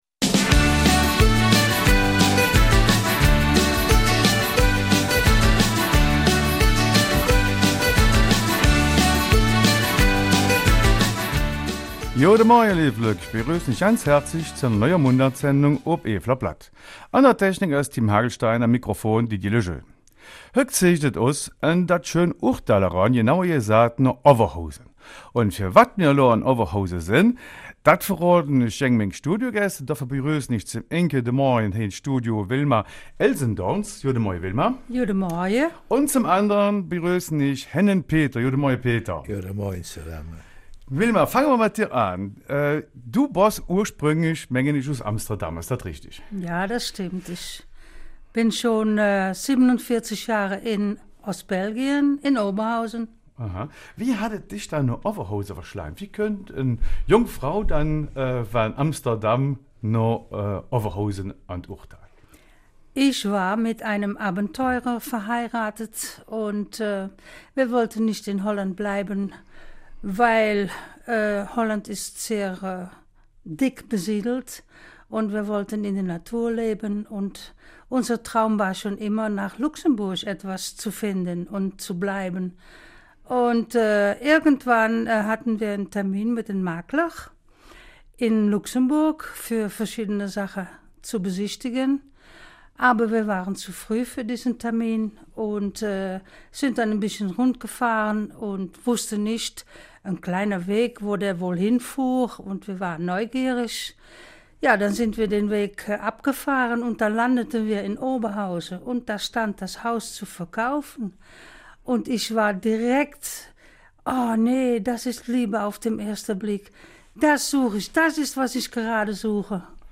Eifeler Mundart: Das Ende der Wohngemeinschaft in Oberhausen